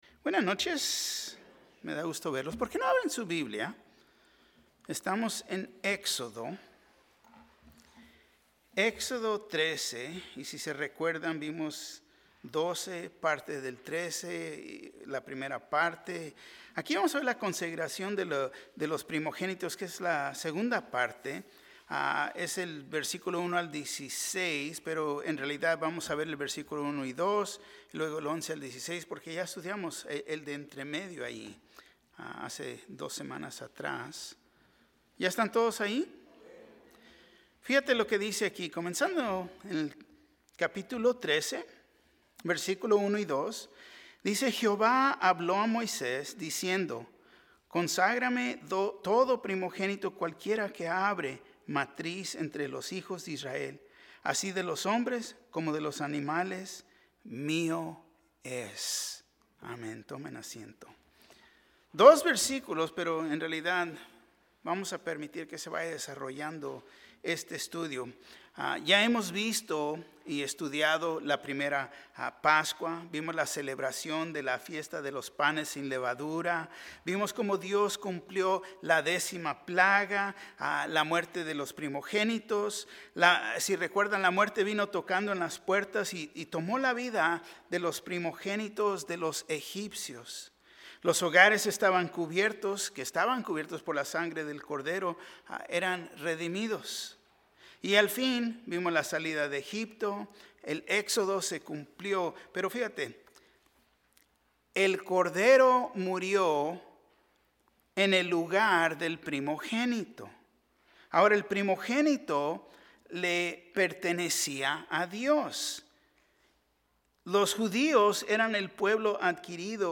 Un mensaje de la serie "Liberados."